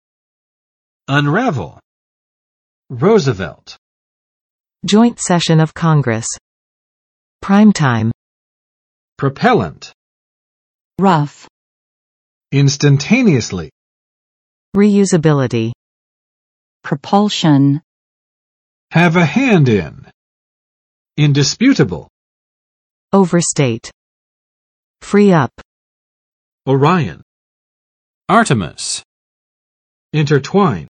[ʌnˋræv!] v. 解开，拆散